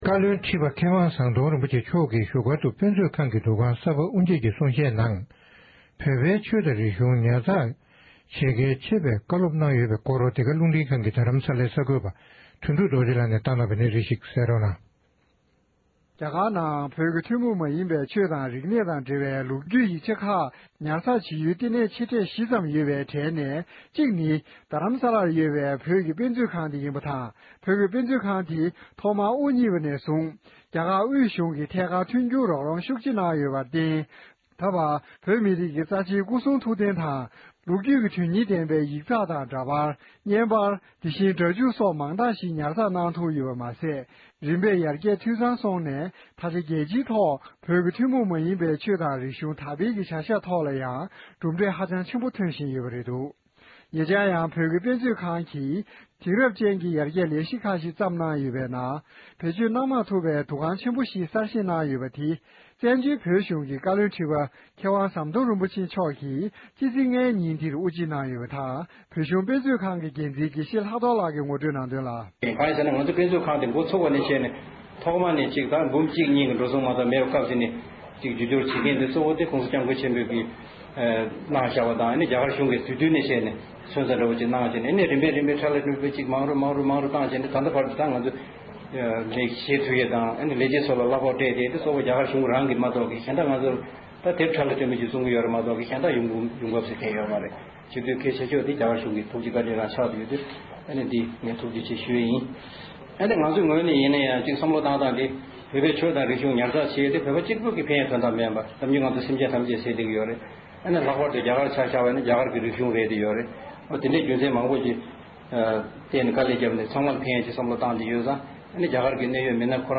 རྡ་ས་བོད་ཀྱི་དཔེ་མཛོད་ཁང་གི་འདུ་ཁང་གསར་པ་དབུ་འབྱེད་ཀྱི་མཛད་སྒོ།
སྒྲ་ལྡན་གསར་འགྱུར། སྒྲ་ཕབ་ལེན།